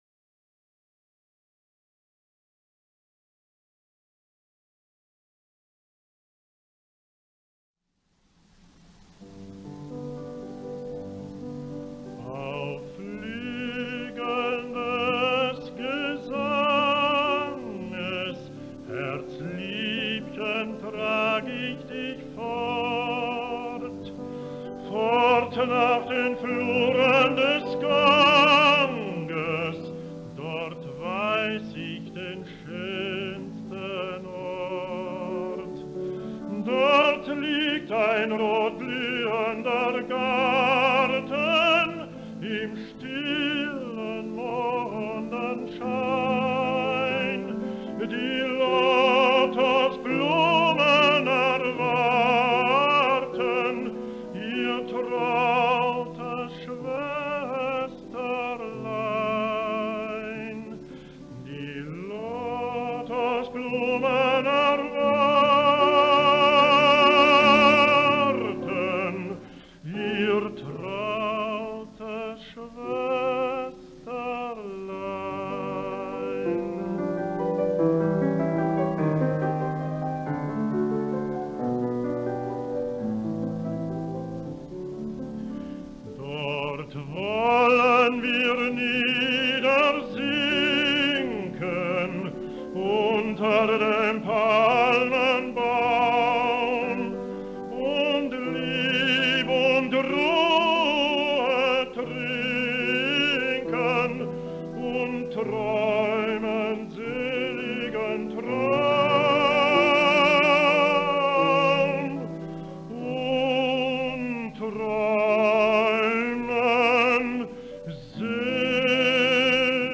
La versió per a mi quasi referencial del tot, deguda a Victoria de los Ángeles la vaig desestimar per estar acompanyada en un preciós arranjament orquestral que semblava que donava prioritat a l’elecció, i per això em vaig estimar més que totes les versions fossin acompanyades a piano.
Va ser un baríton líric especialment reconegut com a cantant verdià a l’Alemanya d’entre guerres.